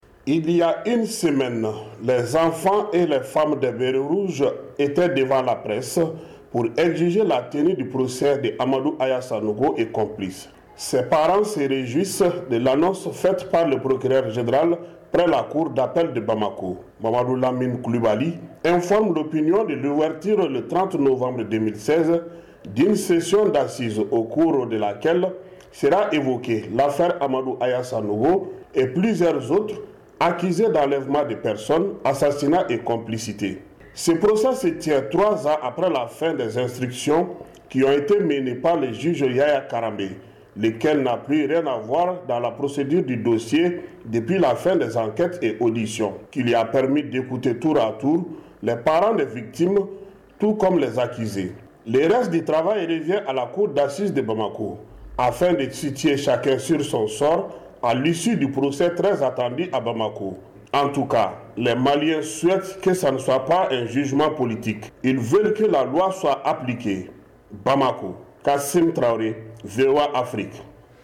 Ouverture du procès de l’ex chef de la junte malienne au 30 Novembre- Reportage